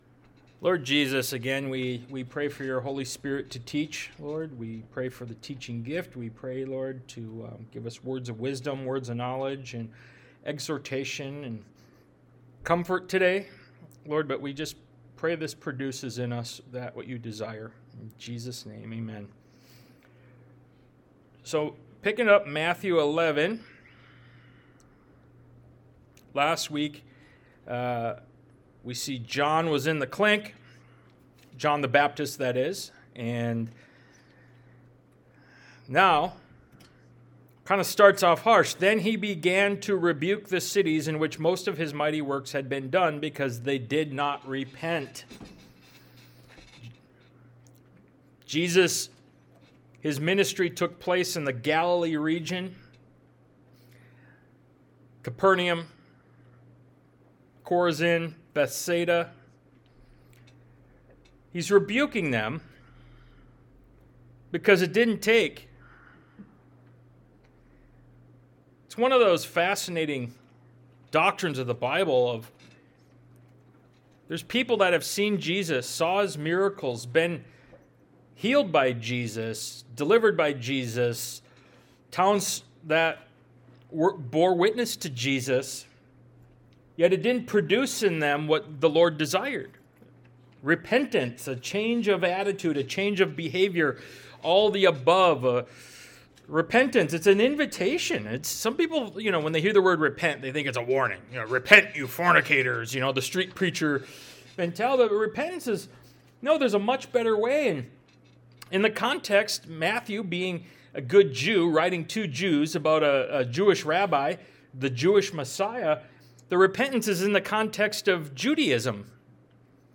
Ministry of Jesus Service Type: Sunday Morning « “Struggling With Doubt” Ministry of Jesus Part 24 “The Unpardonable Sin” Ministry of Jesus Part 26 »